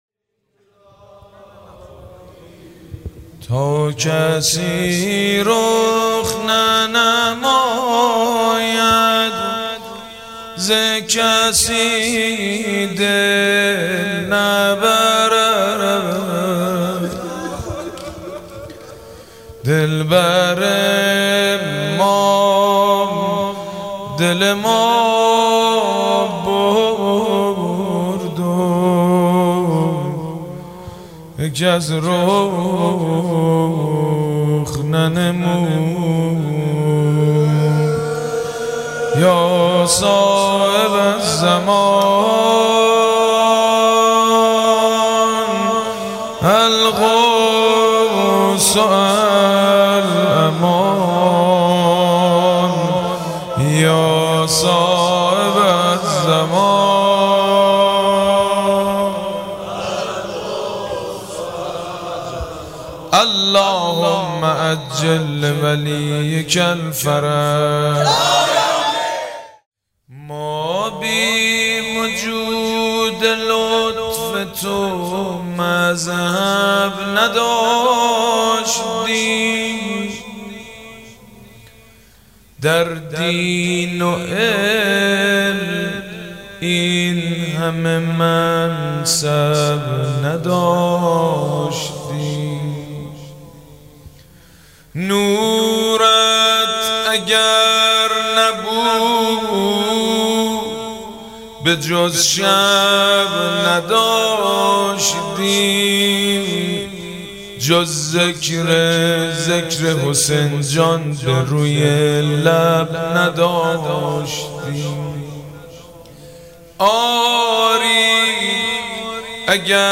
مداحی ویژه شهادت امام صادق (ع) با نوای مجید بنی فاطمه +صوتی
تهران- الکوثر: مداحی ویژه سالروز شهادت بنیانگذار تشیع جعفری حضرت امام صادق (ع) با نوای سیدمجید بنی فاطمه